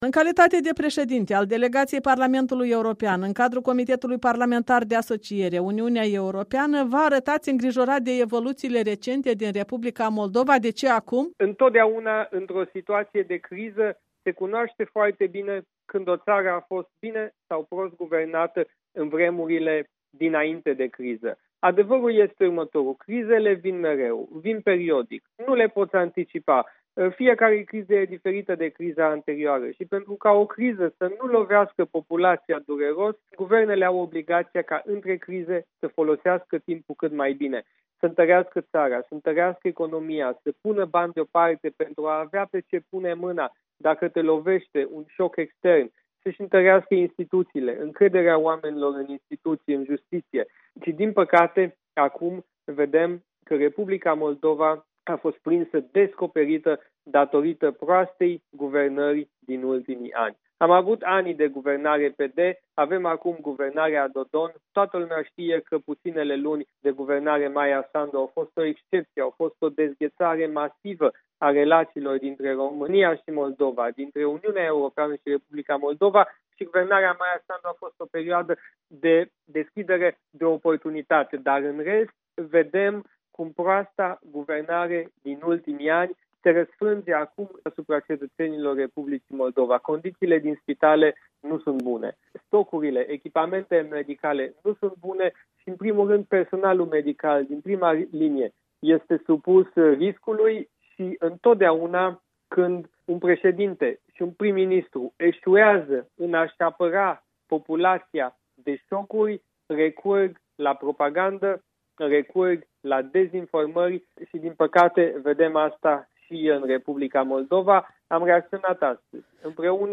Interviu Siegfried Mureșan 30 aprilie 2020